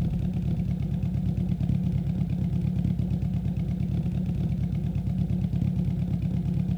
Index of /server/sound/vehicles/lwcars/renault_alpine
idle.wav